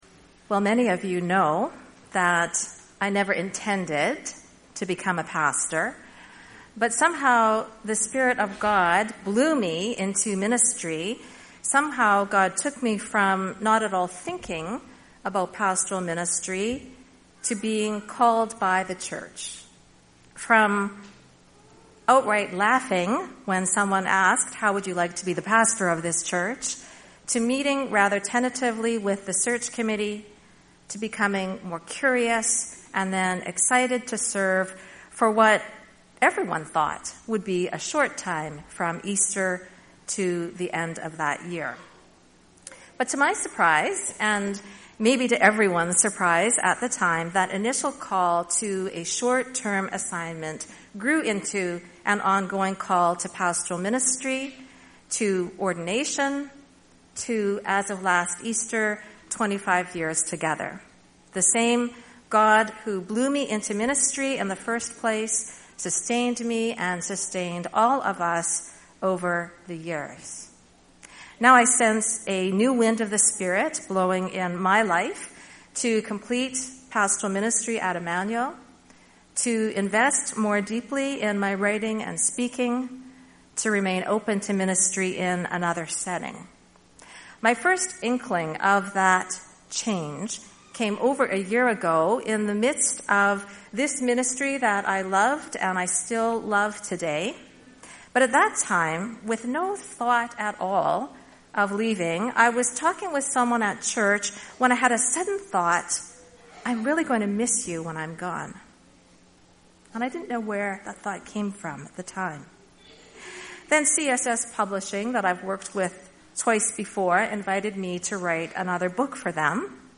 Last Sunday was my last sermon and my last Sunday as lead pastor at my church–such a wonderful day filled with worship, laughter, tears, thoughtfulness, creativity, artistry, love, and beautiful surprises.